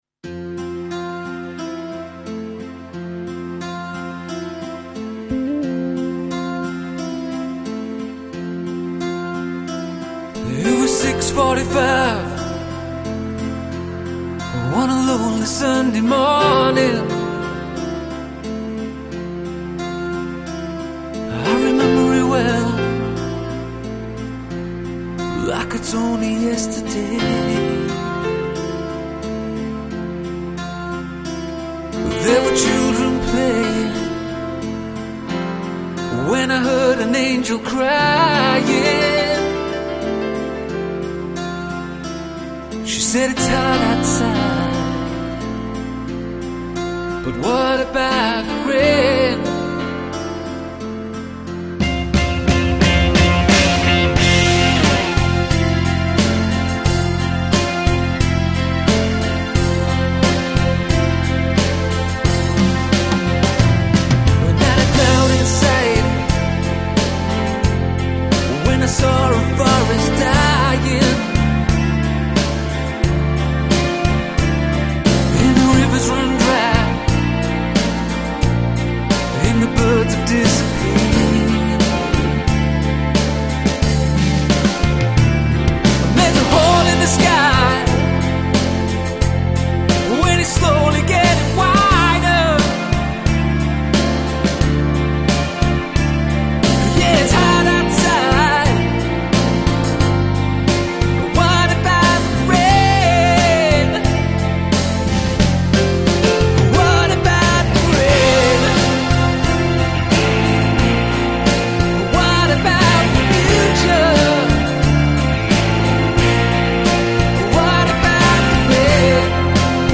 All songs contain their own strong sense of atmosphere.